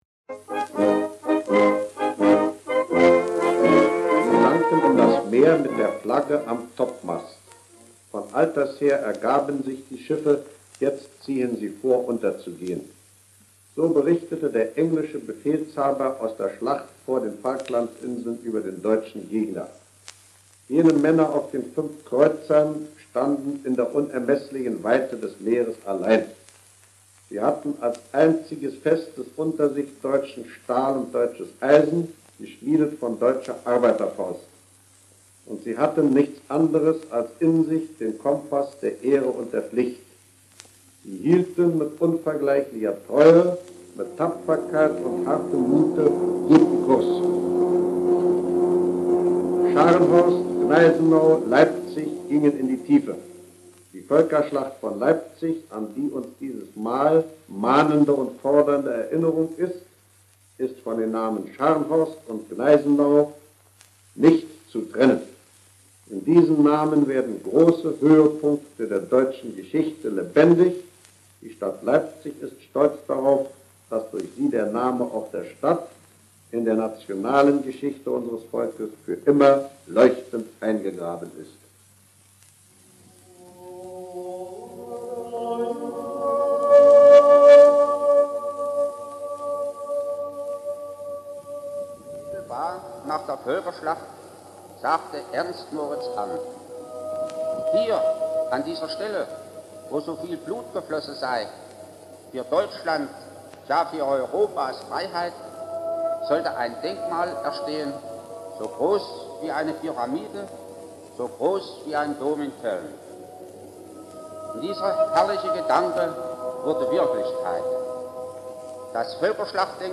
Original Format: Shellac Record 78rpm
Channels: MONO